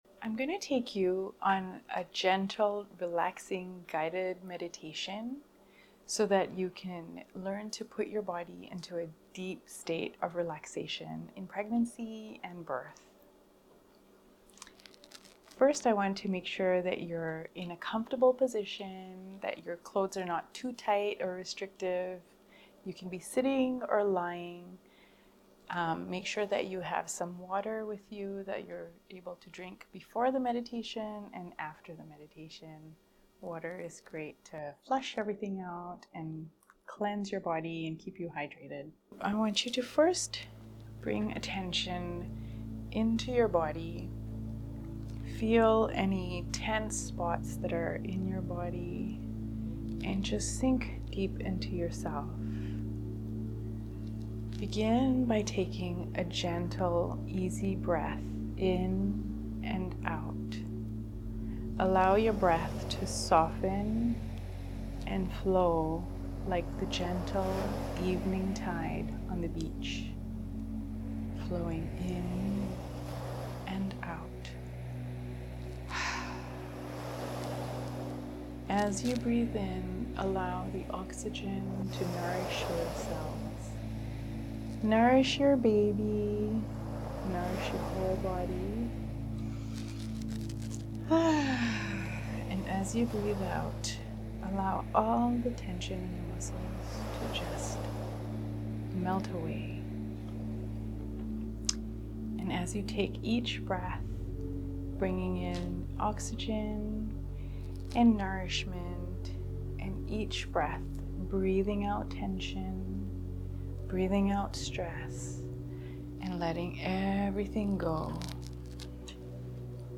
Guided Relaxation Meditation